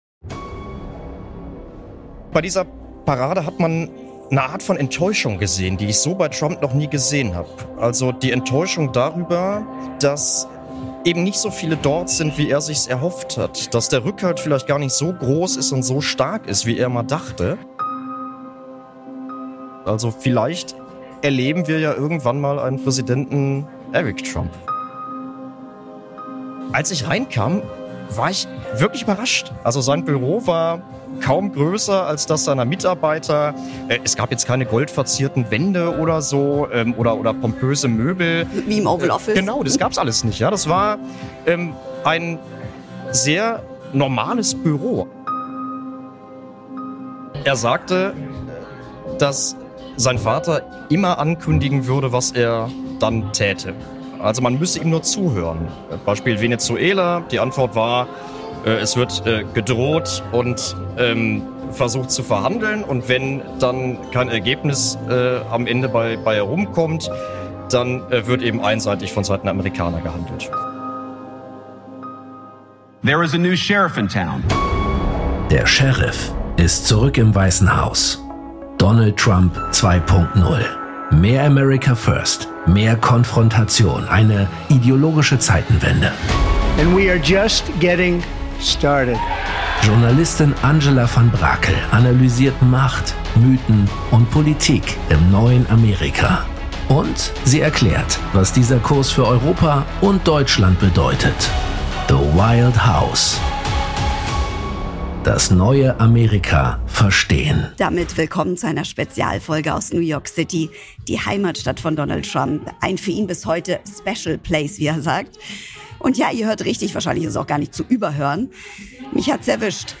Diese Spezialfolge kommt aus New York City, der Stadt, die Donald Trump geprägt hat und die er selbst bis heute als „special place“ bezeichnet. Aufgezeichnet in McGee’s in Manhattan, der Bar, die als Vorlage für die Kultserie „How I Met Your Mother“ diente.